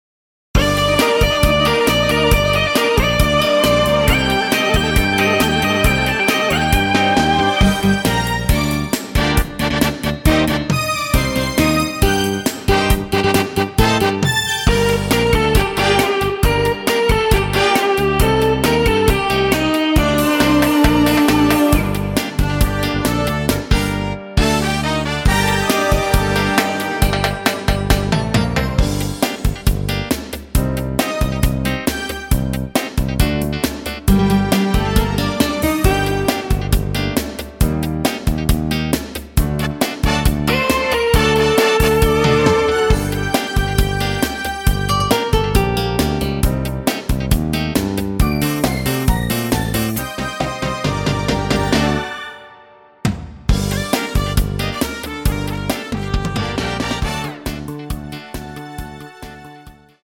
Gm
앞부분30초, 뒷부분30초씩 편집해서 올려 드리고 있습니다.